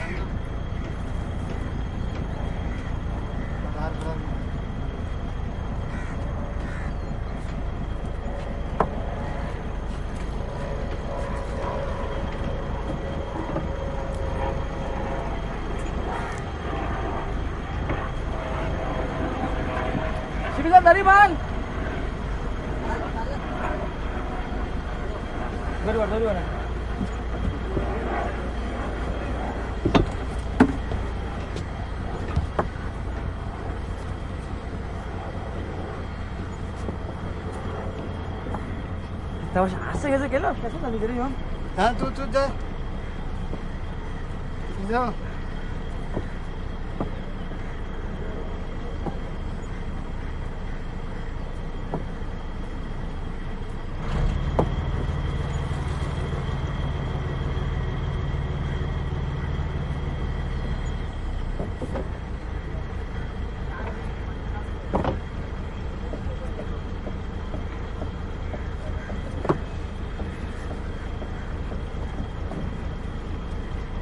rc飞机发动机噪音 " rc飞机飞过
描述：无线电控制燃气发动机的声音
Tag: 发动机噪声 RC-飞机 飞机